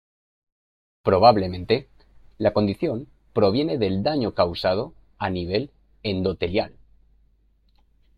/ˈdaɲo/